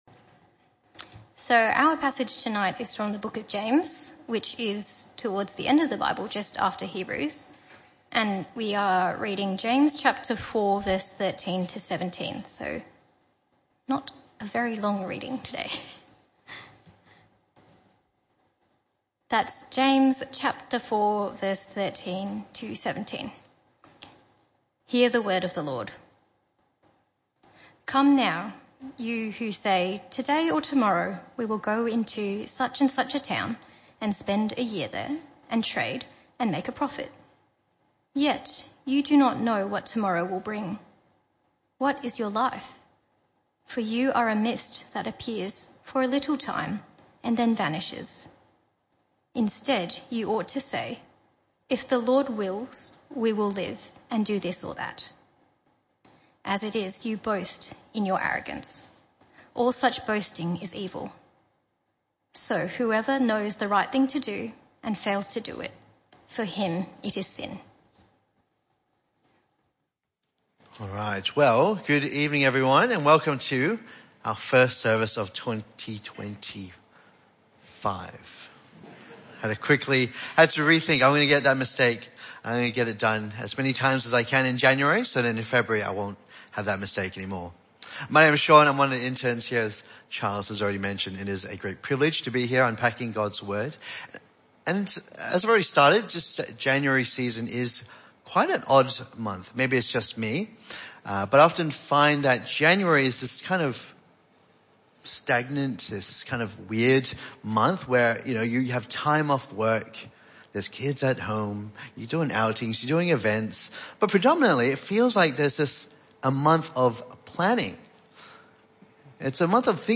Weekly sermons, other bible talks and occasional special events from WPC Bull Creek